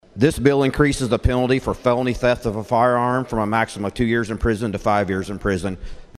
CLICK HERE to listen to details from House member John George.